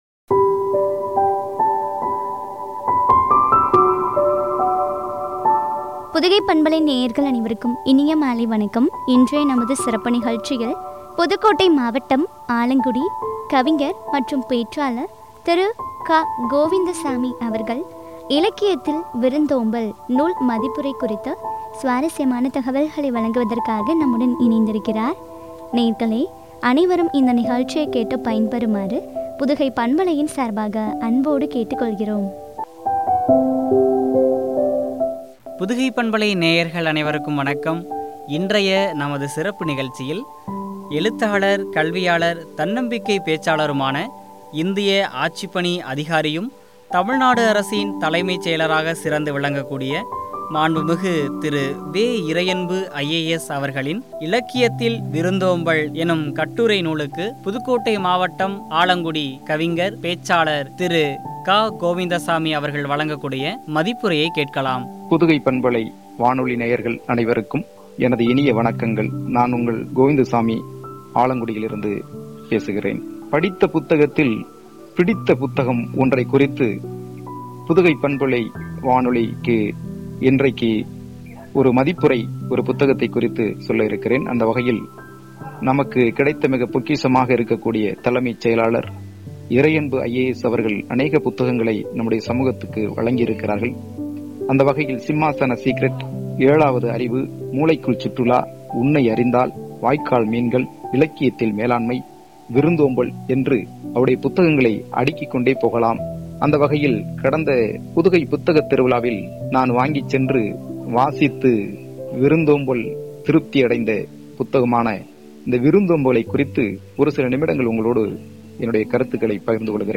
நூல் மதிப்புரை பற்றி வழங்கிய உரையாடல்.